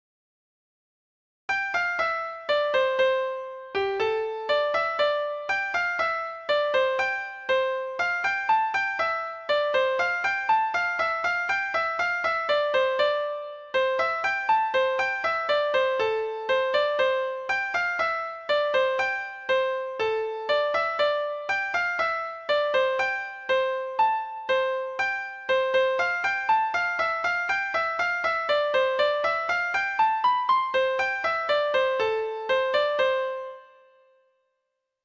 Traditional Trad. I Love My Love in Secret Treble Clef Instrument version
Folk Songs from 'Digital Tradition' Letter I I Love My Love in Secret
Traditional Music of unknown author.